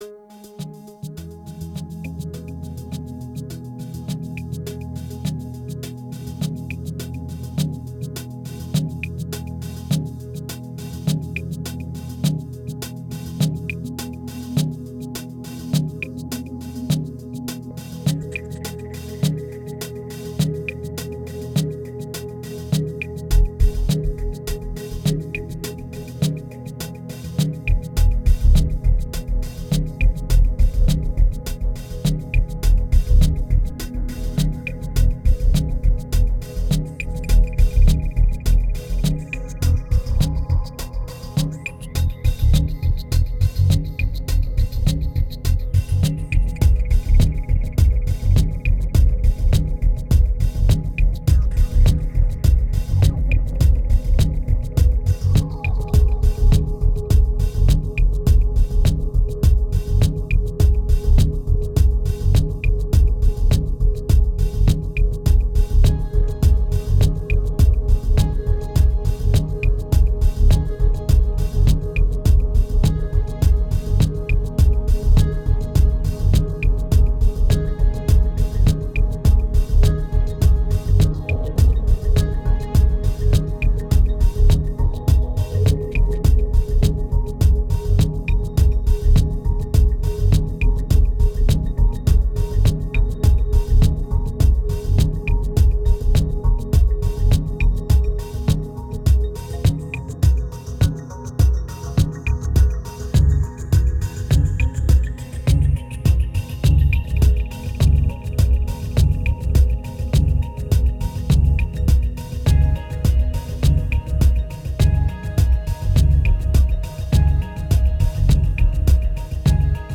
Drowning under massive FX, there still is a part of truth.